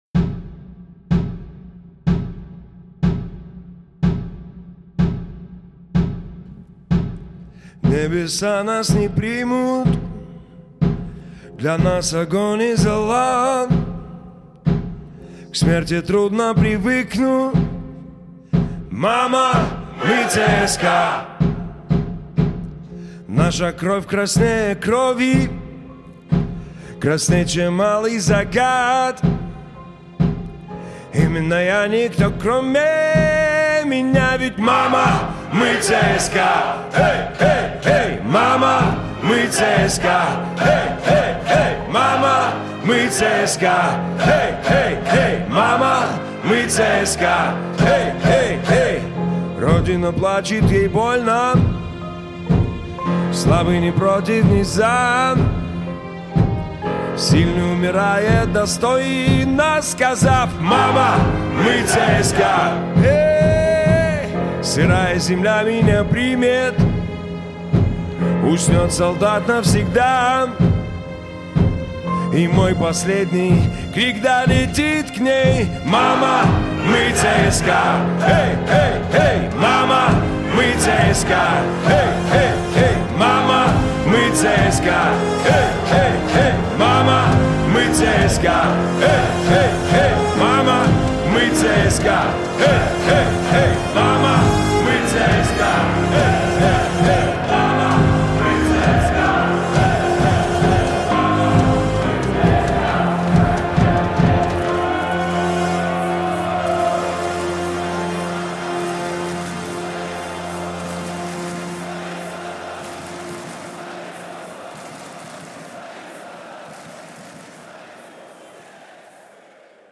Жанр: Русский рэп / Хип-хоп
• Жанр песни: Русский рэп / Хип-хоп